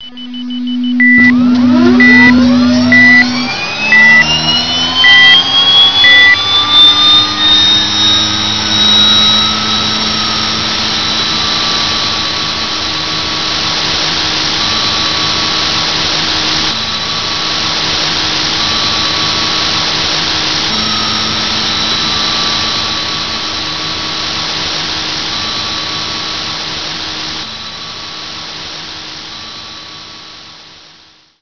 starter.wav